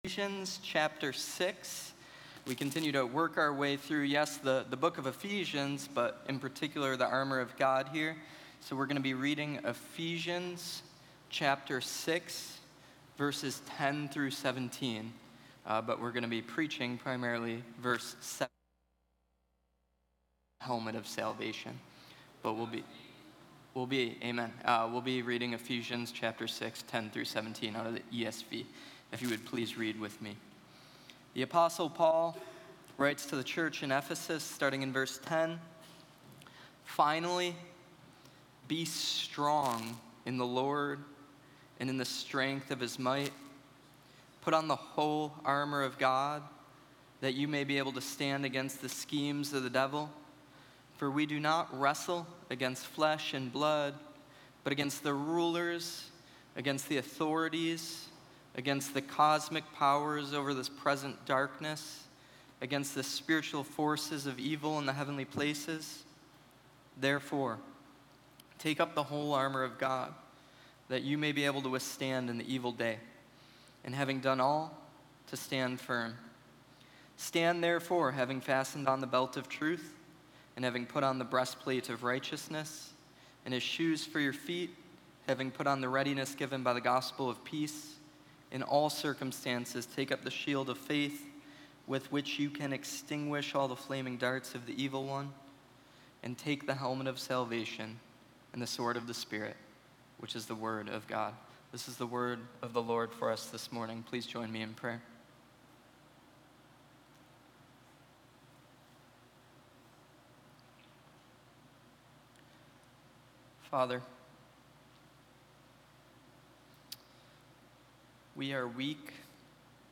In this message from Ephesians 6:16, we explore how faith protects us from the attacks of the enemy and gives us strength to stand firm in spiritual battle.